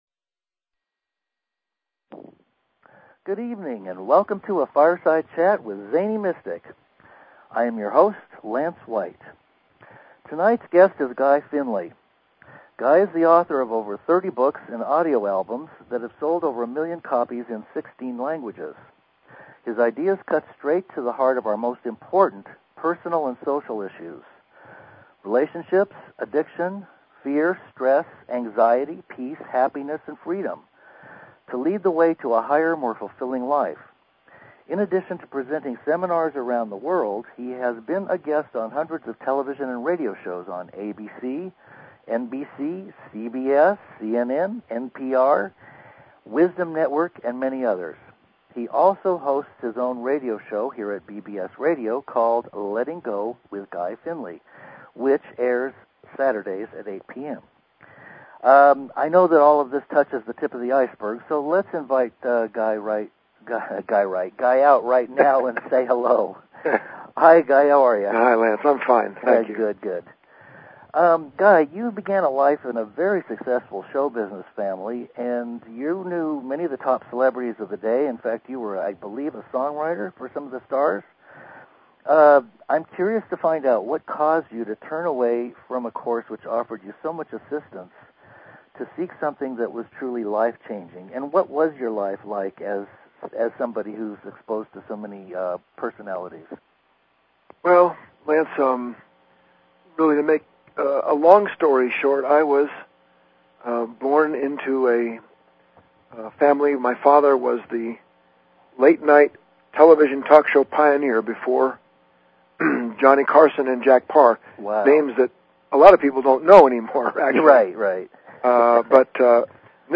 Guest, Guy Finley